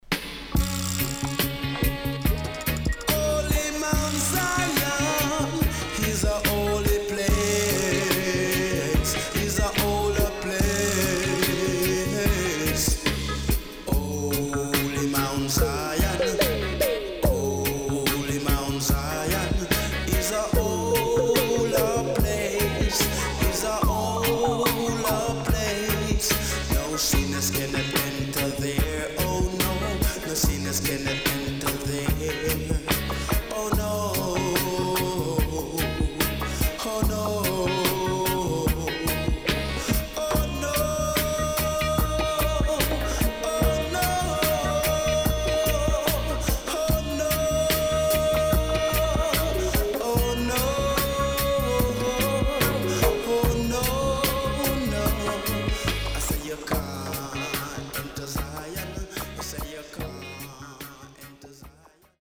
HOME > LP [DANCEHALL]
SIDE A:少しチリノイズ入りますが良好です。
SIDE B:少しチリノイズ入りますが良好です。